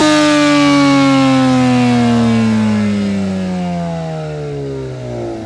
rr3-assets/files/.depot/audio/Vehicles/f1_01/f1_01_decel.wav
f1_01_decel.wav